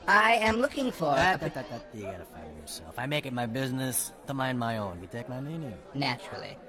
―8t88 learns the bouncer's motto on privacy. — (audio)